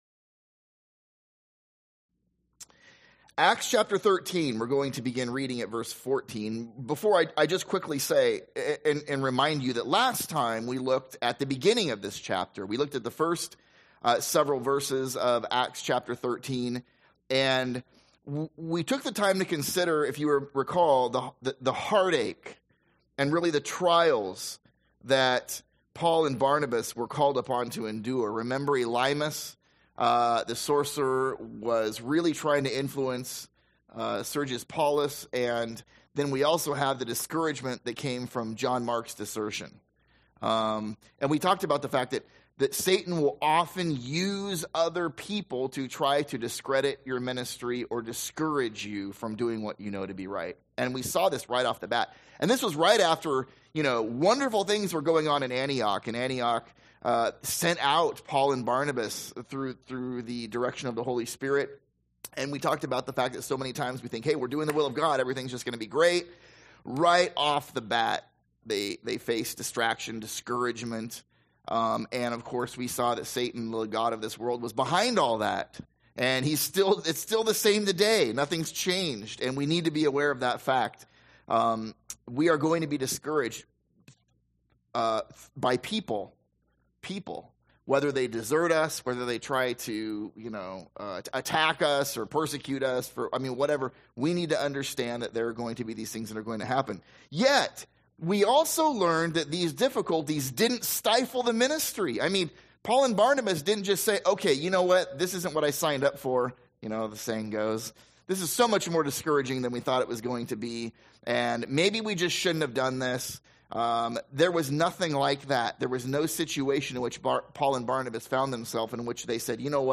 / A Sunday School series through the book of Acts.